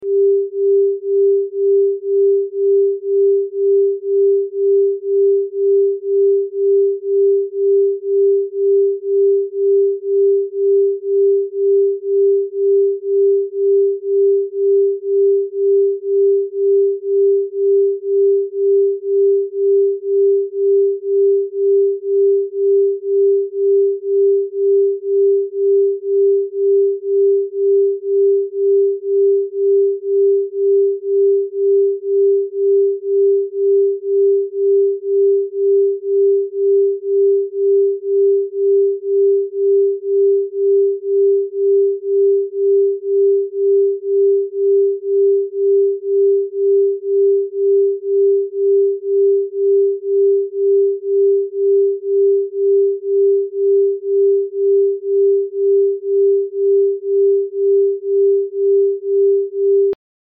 396 Hz - LA FRECUENCIA QUE LIBERA MIEDOS Y RAÍCES TÓXICAS